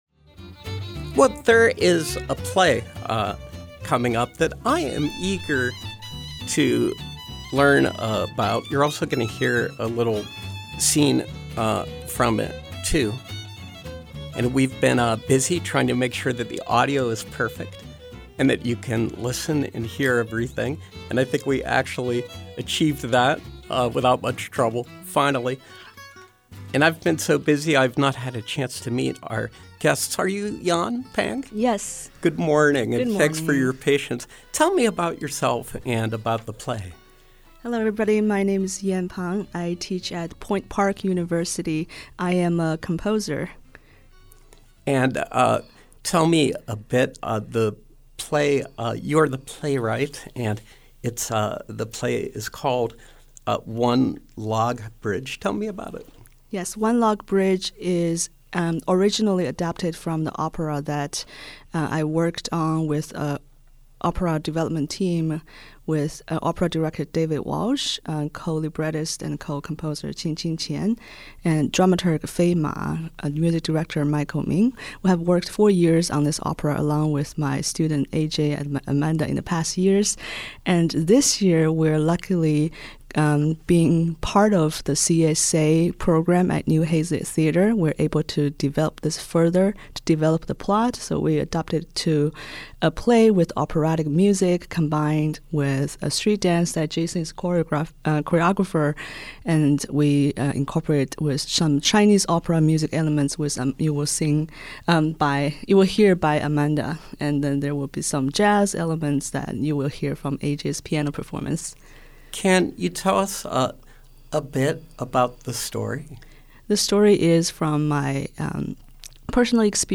Interview: CSA Performance Series, One Log Bridge